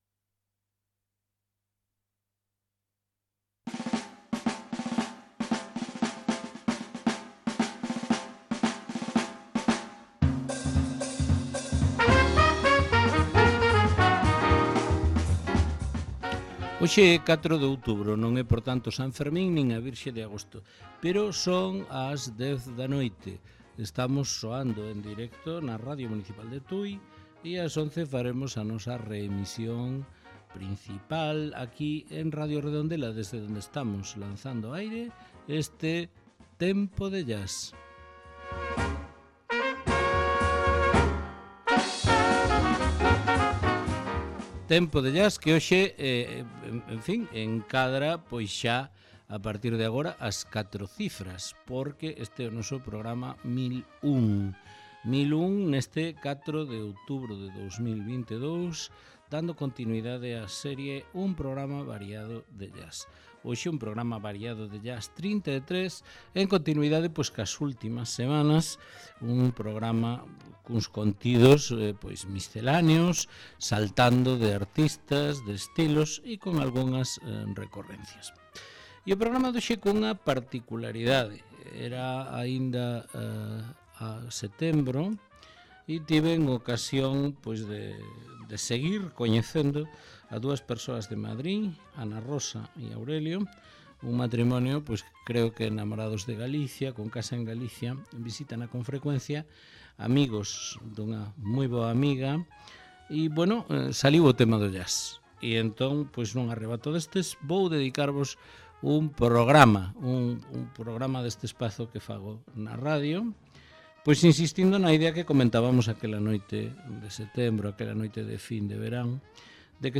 Tempo de Jazz 4/10/2022: Un programa variado de Jazz XXXIII